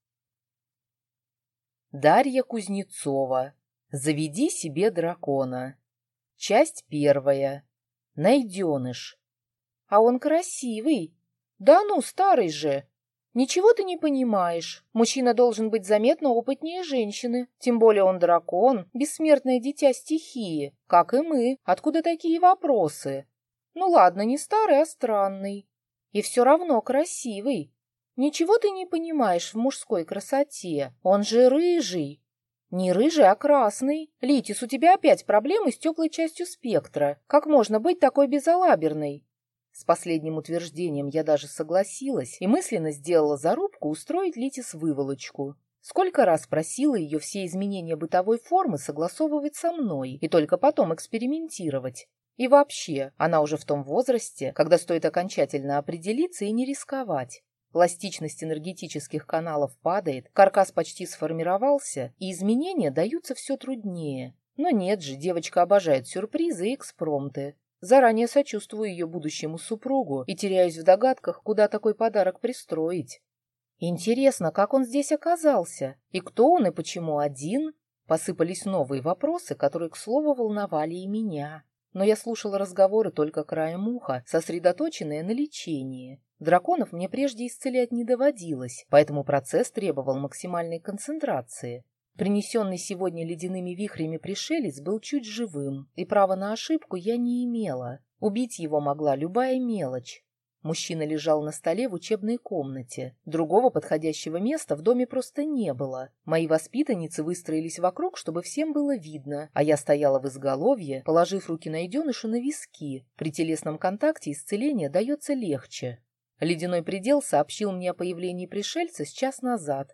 Аудиокнига Заведите себе дракона | Библиотека аудиокниг